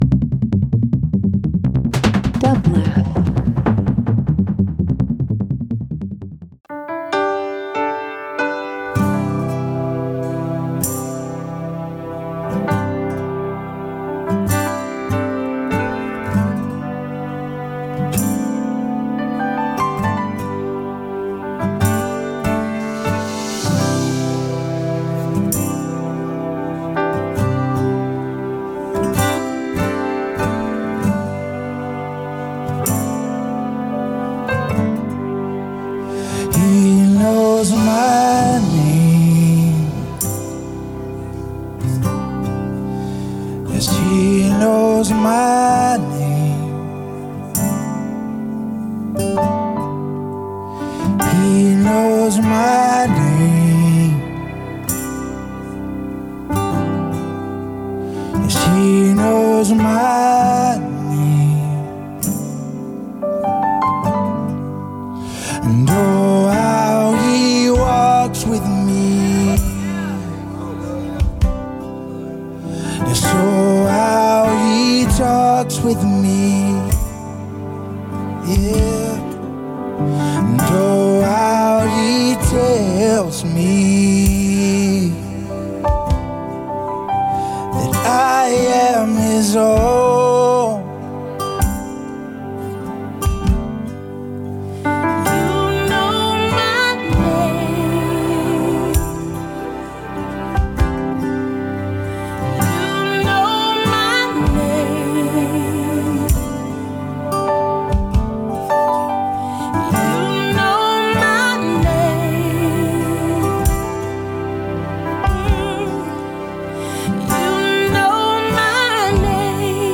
Pop R&B Soul